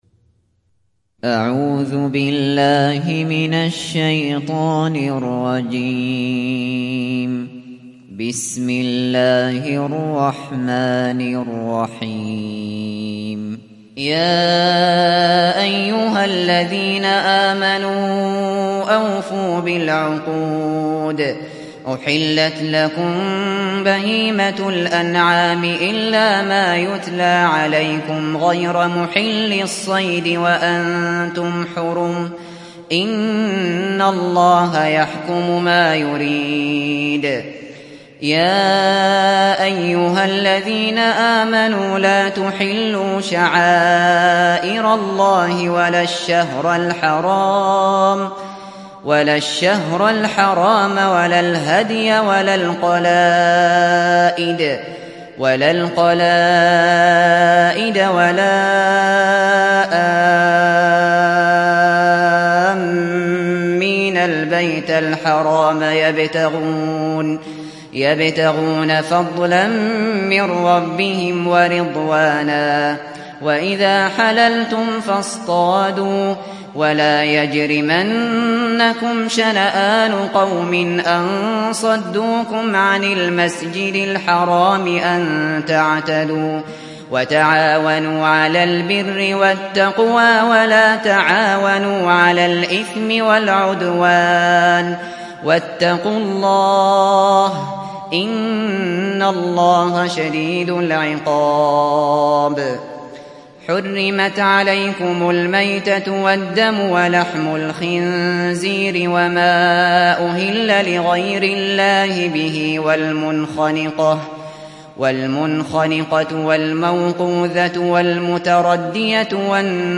Sourate Al Maidah mp3 Télécharger Abu Bakr Al Shatri (Riwayat Hafs)